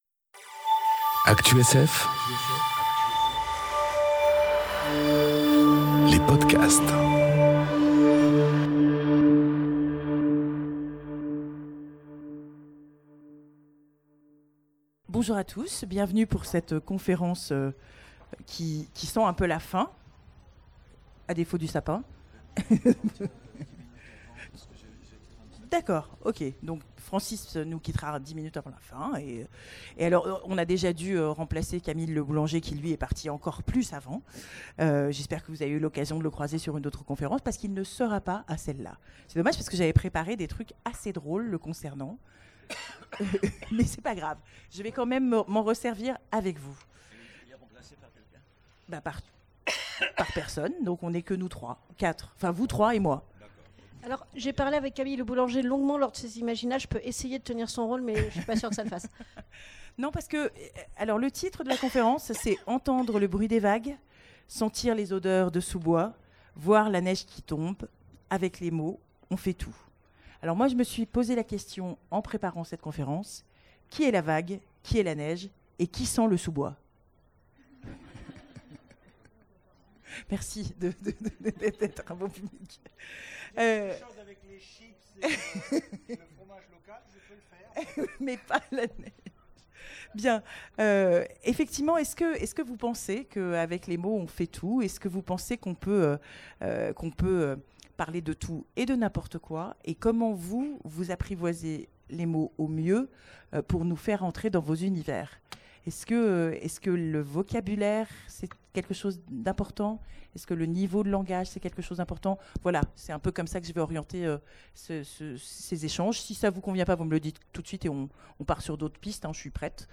Conférence Entendre le bruit des vagues, sentir l'odeur des sous-bois, voir la neige qui tombe... Avec les mots, on fait tout ! enregistrée aux Imaginales 2018